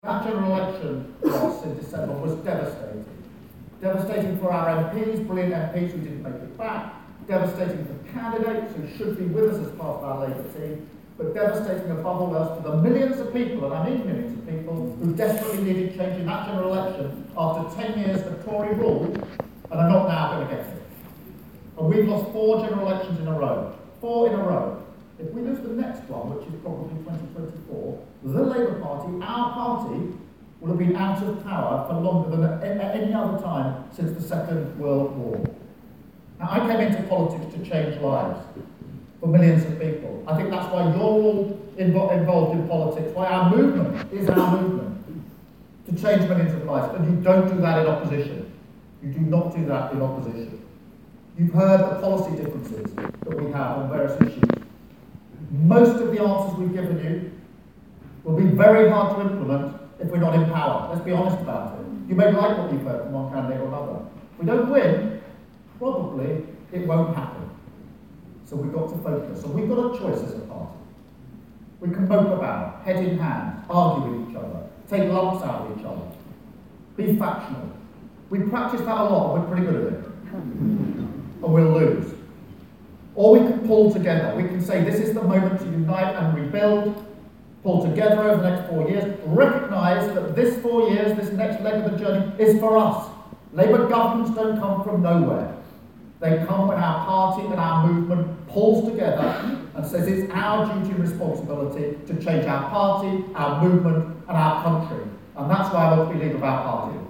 LISTEN: Sir Kier Starmer speaks at final hustings event in Labour leadership contest - 01/03/20